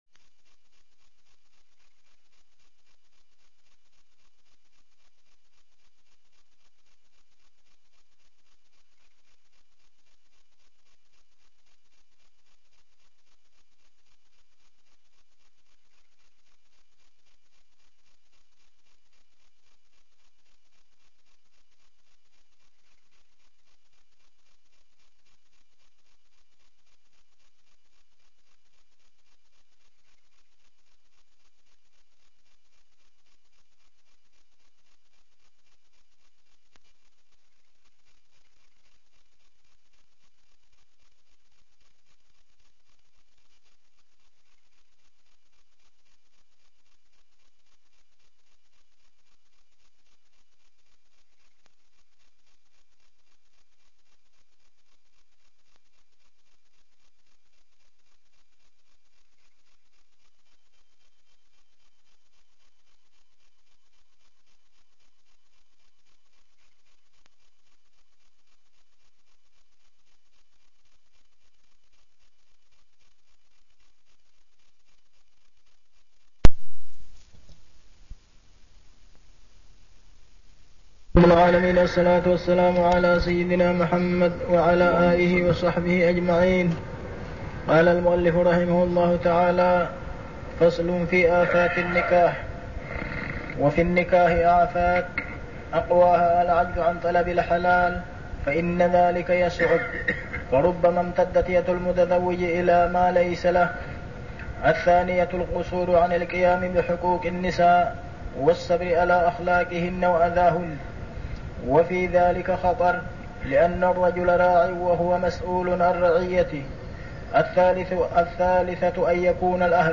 الدروس | موقع الشيخ صالح بن حميد